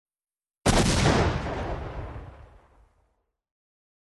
Звуки снайперской винтовки: выстрел среди деревьев с эхом